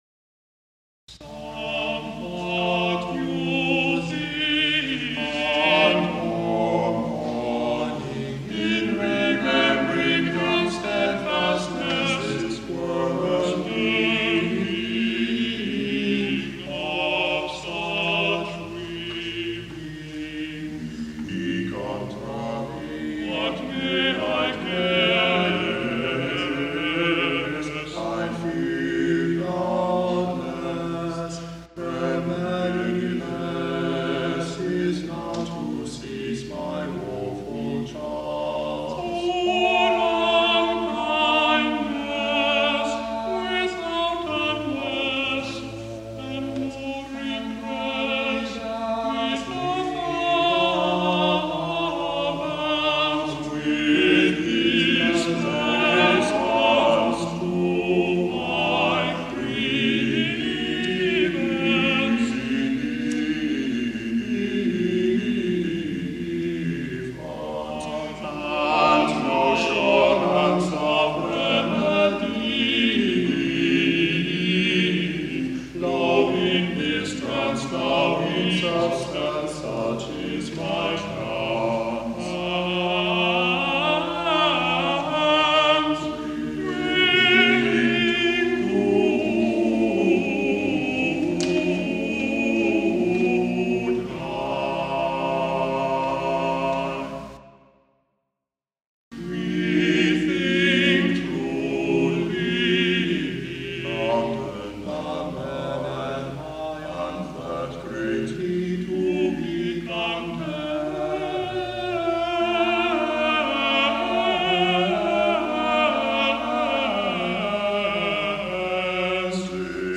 Vocal Ensemble 'Cloth of Gold' 1972
tenor
baritone
bass